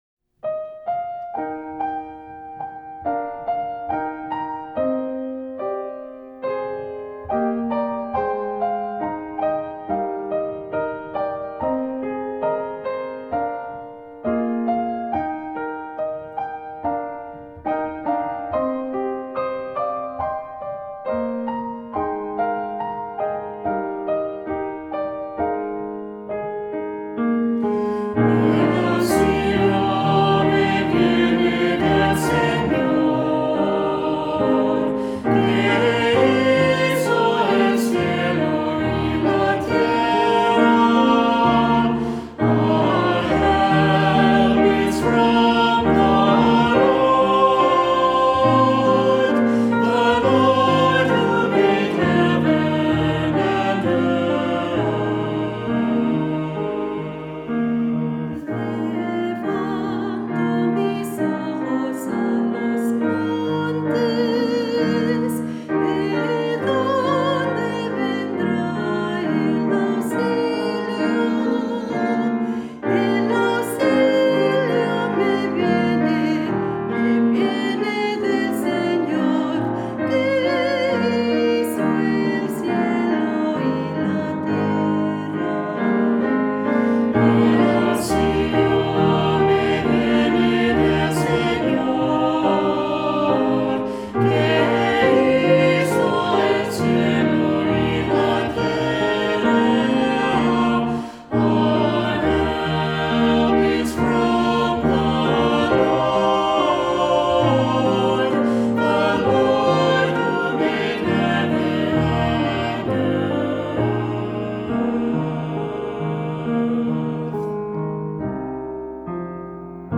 Voicing: Cantor, descant,SATB, assembly